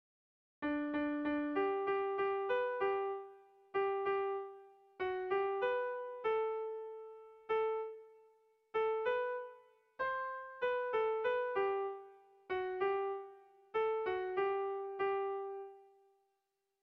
Lauko txikia (hg) / Bi puntuko txikia (ip)
AB